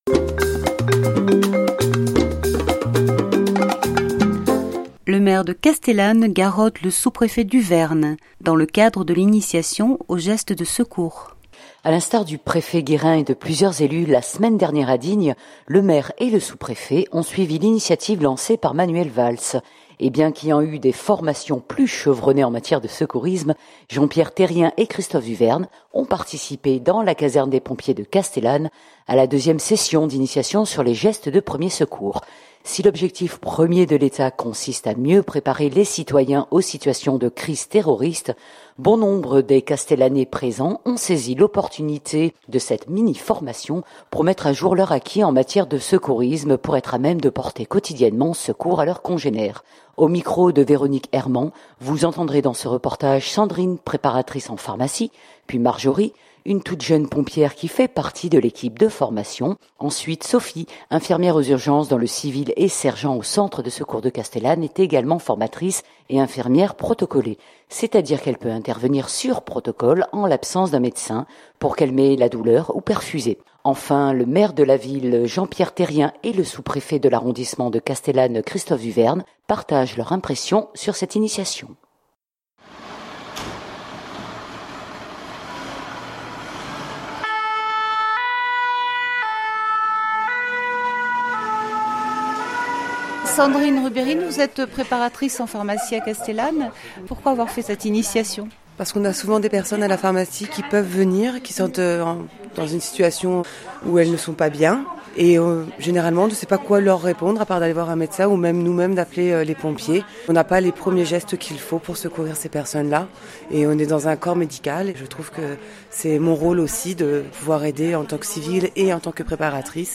Et bien qu’ayant eu des formations plus chevronnées en matière de secourisme, Jean-Pierre Terrien et Christophe Duverne ont participé, dans la caserne des pompiers de Castellane, à la deuxième session d’initiation sur les gestes de premiers secours.
Enfin le maire de la ville, Jean-Pierre Terrien et le sous-préfet de l’arrondissement de Castellane, Christophe Duverne, partagent leurs impressions sur cette initiation.